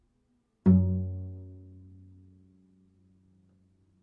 小提琴：单音 拨弦 G4 G5 " 小提琴 F5 拨弦 非颤音
描述：这是一个小提琴在第五个八度演奏F调的乐器样本。这个音符的攻击和延音是拨弦和非颤音。所用的调谐频率（音乐会音高）是442，动态意图是中音。这个样本属于一个多样本包 乐队乐器。小提琴乐器。chordophone和弦乐器。弦乐器音符：F八度。5音乐会音高：442Hz动态：Mezzoforte攻击。攻击：拨弦持续。非振动麦克风。ZoomH2N话筒设置。XY
标签： 多重采样 放大H2N的 非颤音 拨弦 mezzoforte F-尖5 串仪器 弦鸣乐器 小提琴
声道立体声